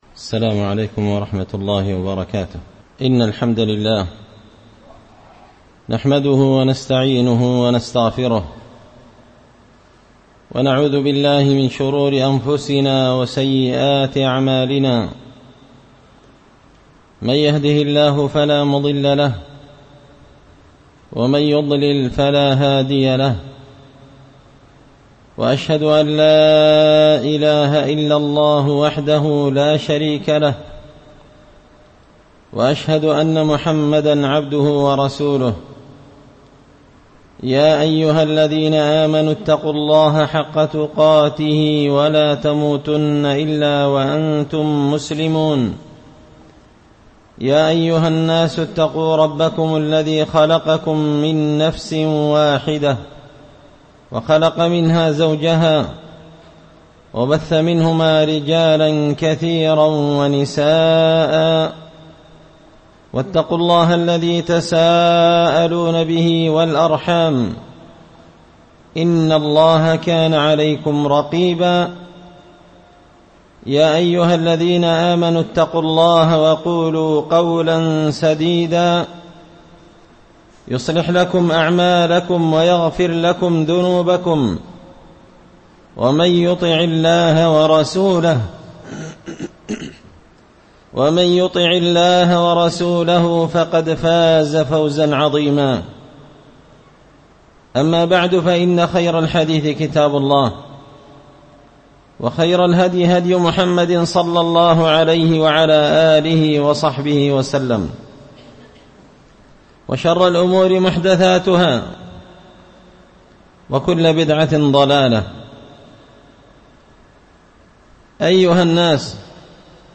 خطبة جمعة بعنوان _صفة وضوء النبي صلى الله عليه وسلم_10ربيع الثاني 1444هـ